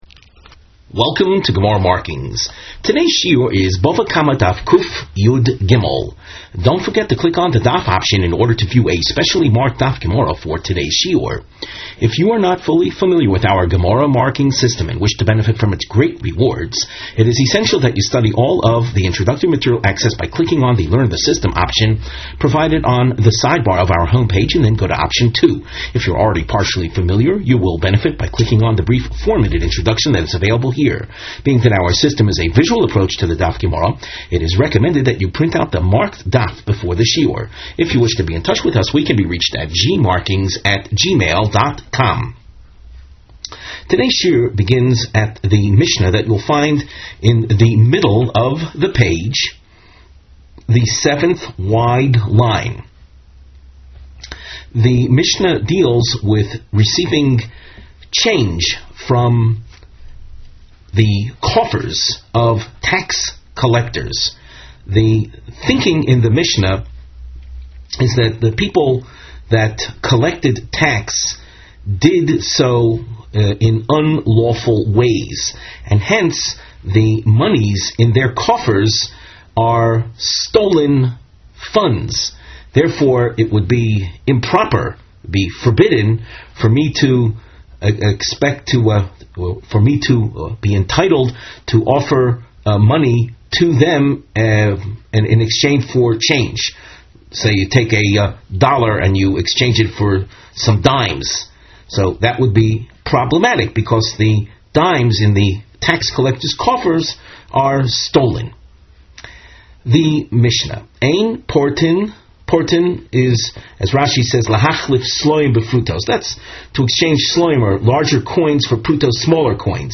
Please click on the above video to hear the Rav give the shiur.